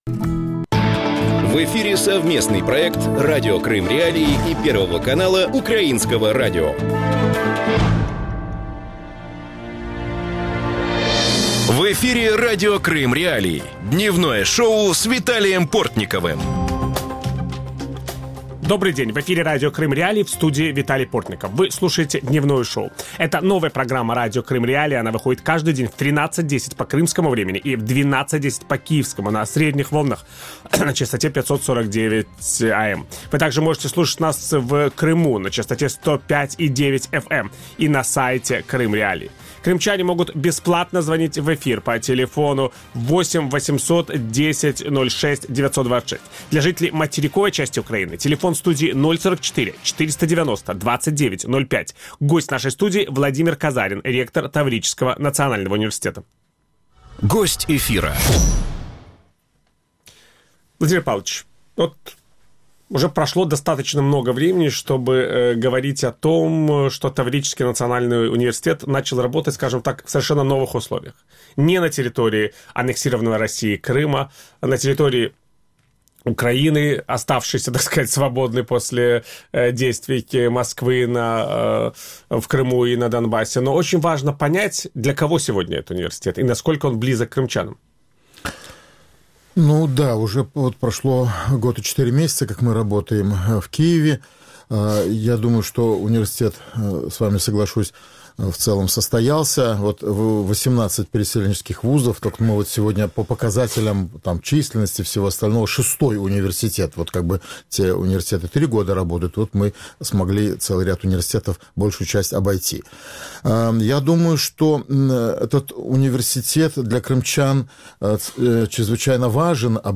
Ведущий –Виталий Портников.